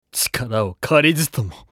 男性
厨二病ボイス～戦闘ボイス～